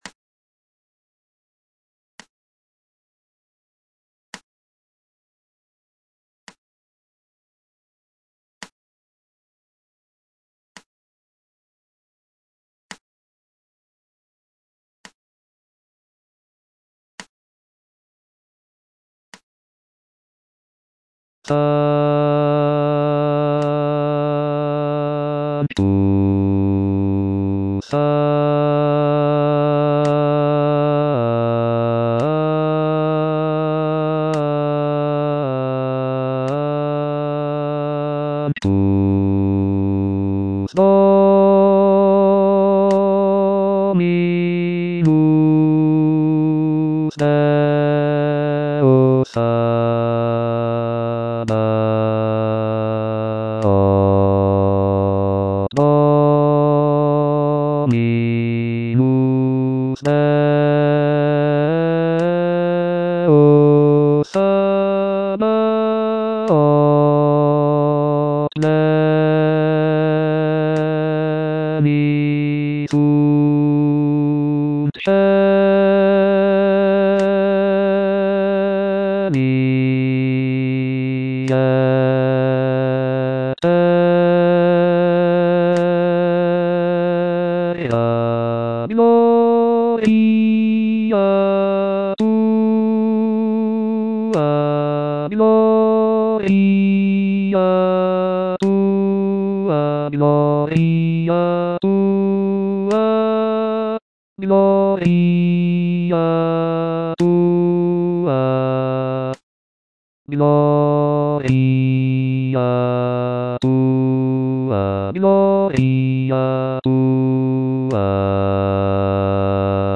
T.L. DE VICTORIA - MISSA "O MAGNUM MYSTERIUM" Sanctus - Bass (Voice with metronome) Ads stop: auto-stop
It is renowned for its rich harmonies, expressive melodies, and intricate counterpoint.